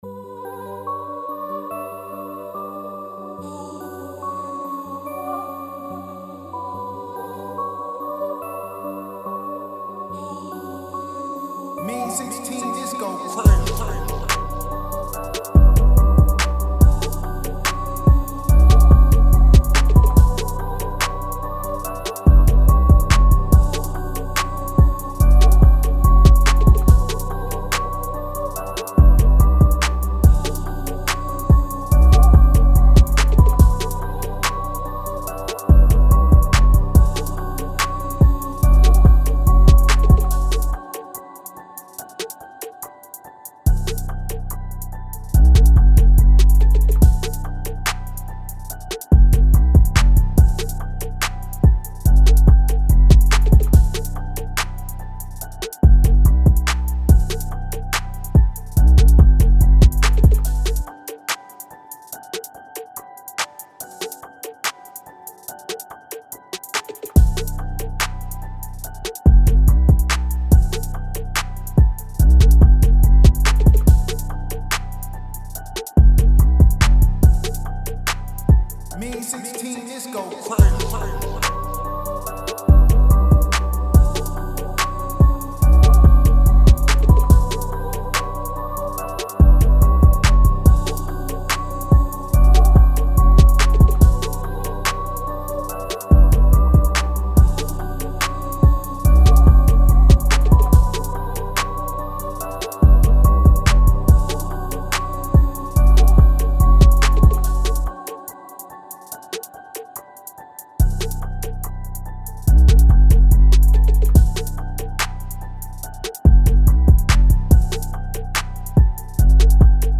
B-Min 143-BPM